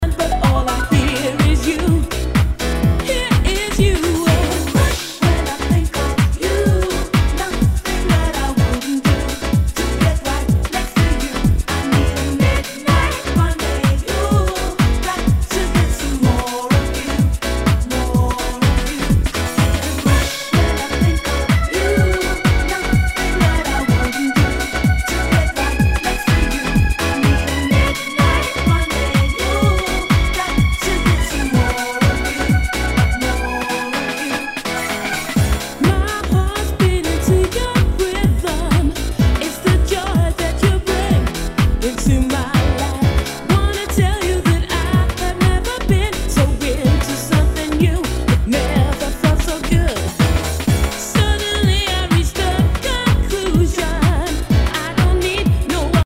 HOUSE/TECHNO/ELECTRO
ナイス！ヴォーカル・ハウス！